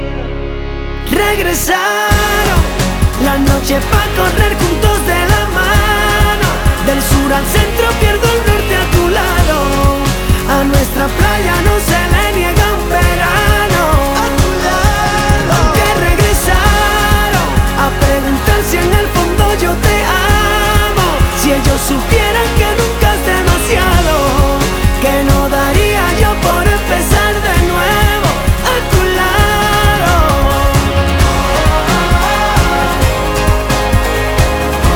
Latin